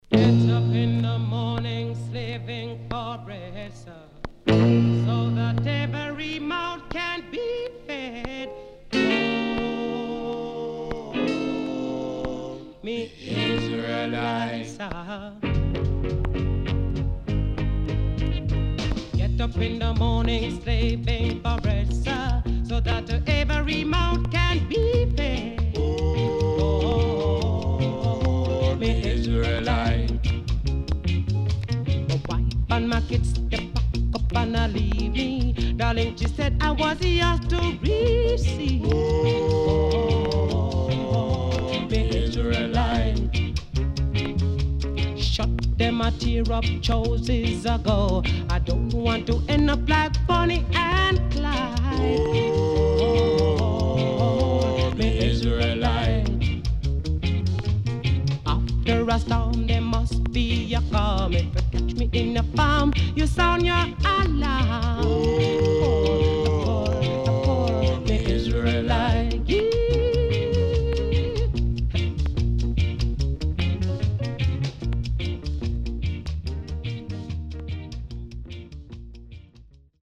CONDITION SIDE A:VG(OK)〜VG+
SIDE A:うすいこまかい傷ありますがノイズあまり目立ちません。